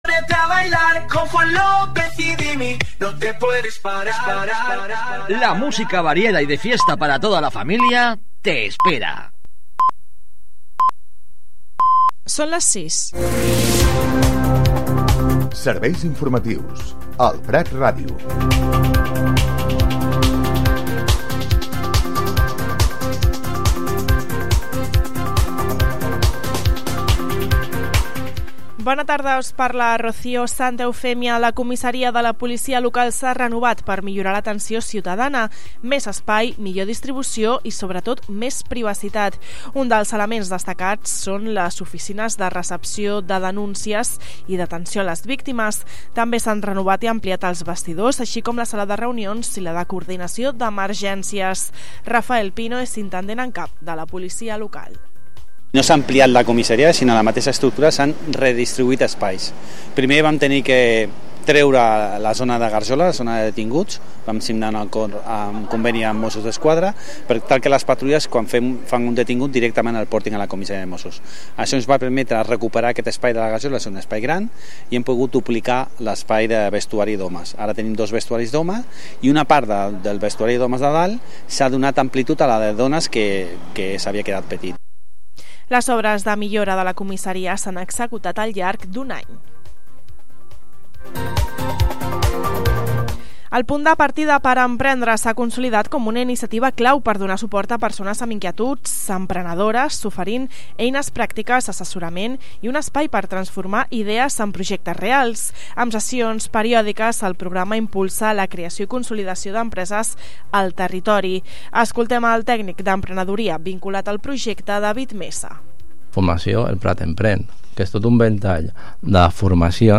Butlletí de les 18:00 h